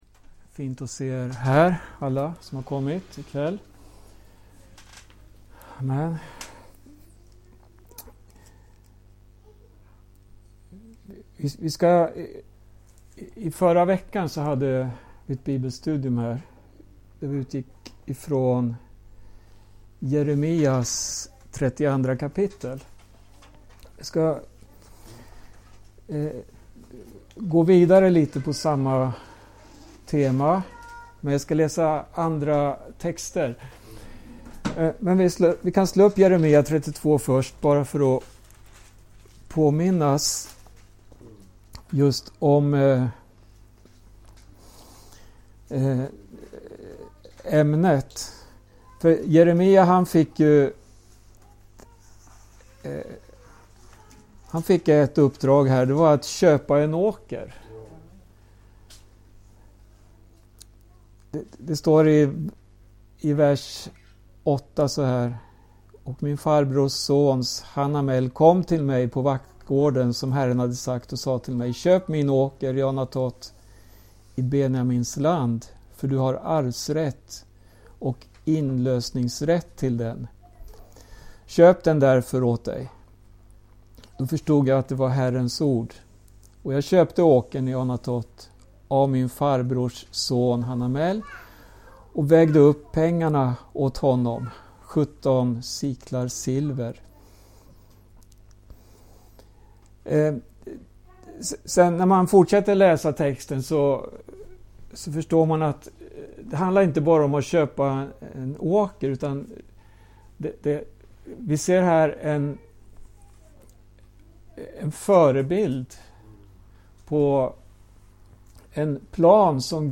Inspelat i Skälby 13 september 2024 kl 18.